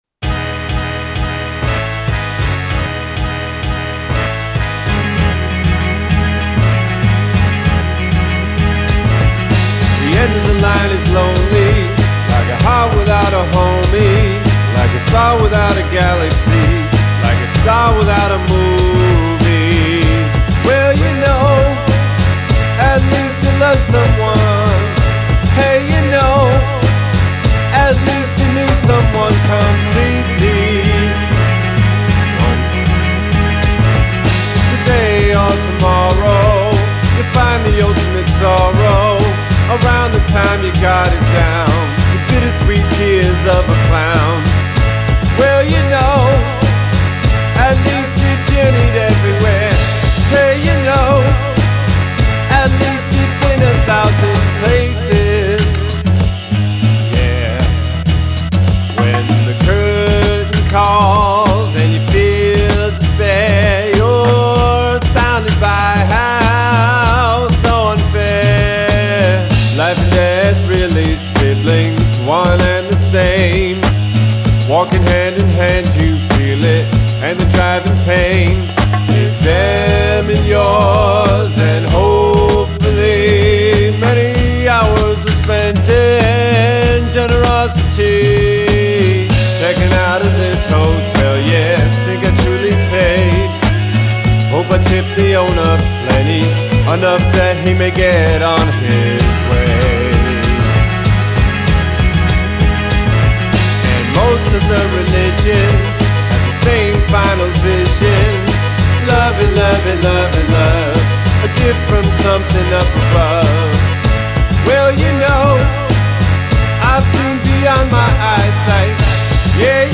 une voix à la Morrissey, de la pop, de la soul